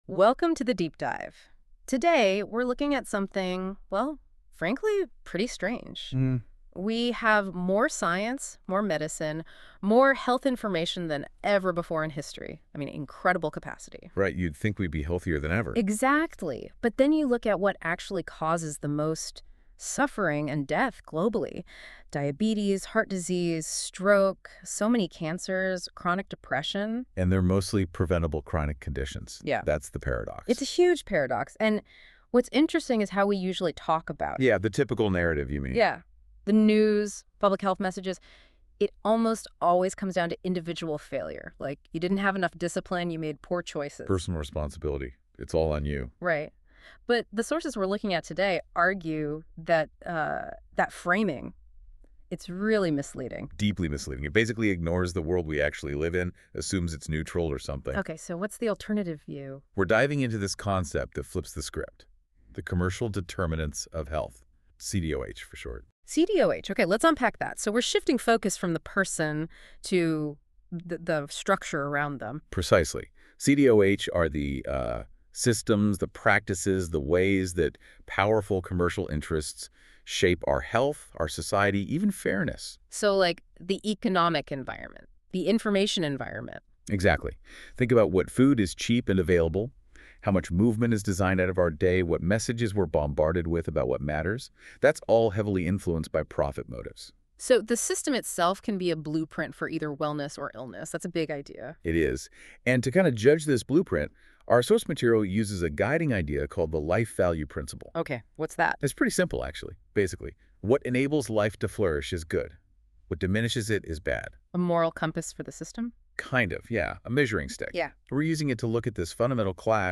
The Commercial Determination of Disease and the Loss of Health Sovereignty: A Life-Value Analysis of the Present Disorder and the Conditions of its Resolution | ChatGPT5 & NotebookLM - TOWARDS LIFE-KNOWLEDGE
Deep Dive Audio Overview